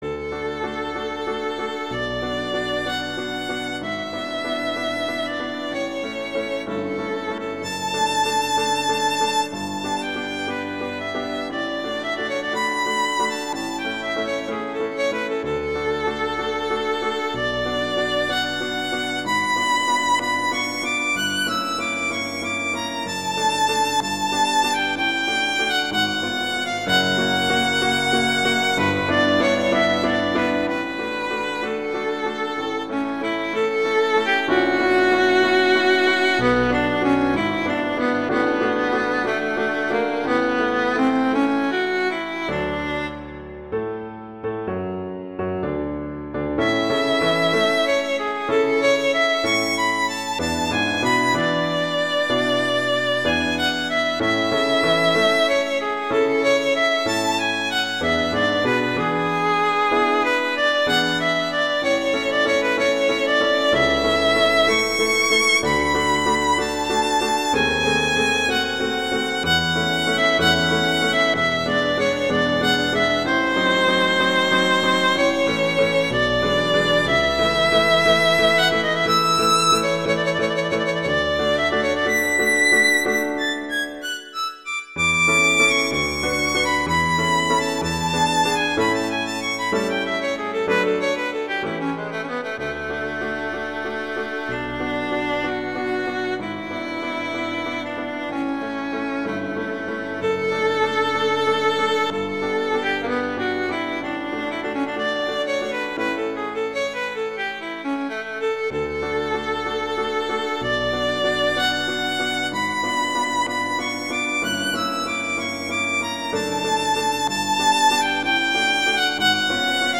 classical
♩=63 BPM